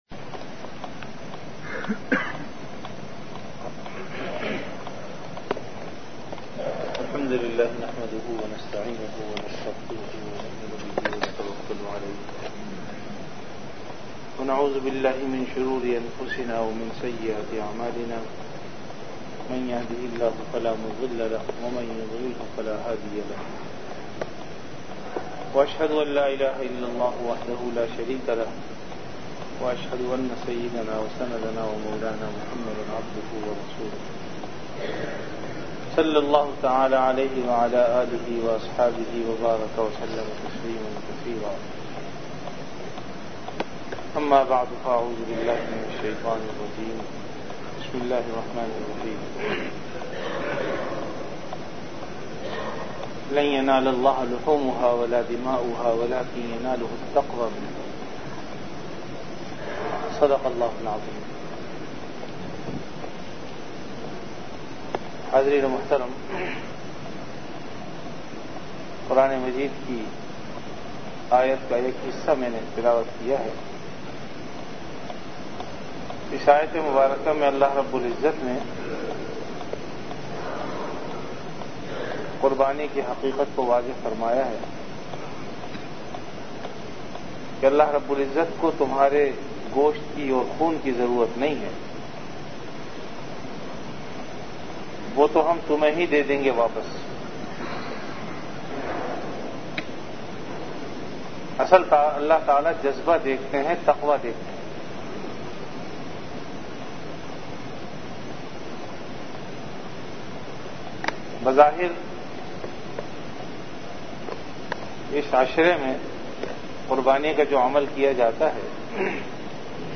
Bayanat
Event / Time After Isha Prayer